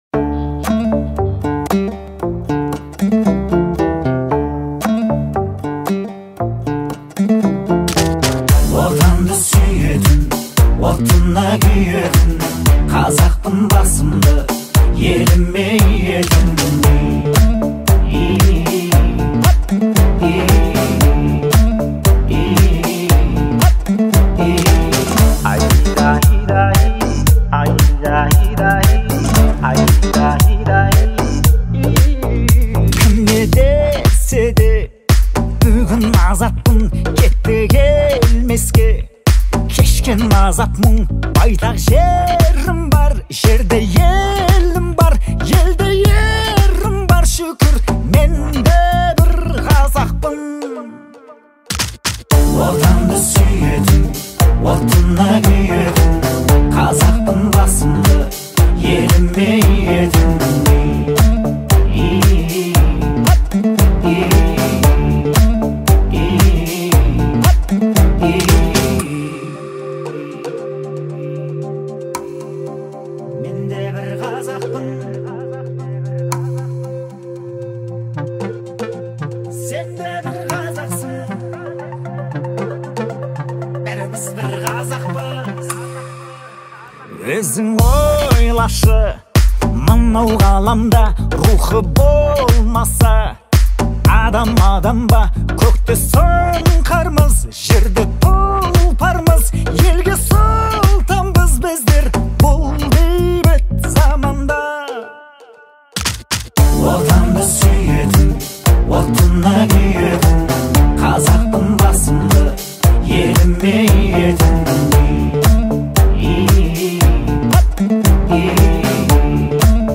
выделяется мощным вокалом и харизматичным стилем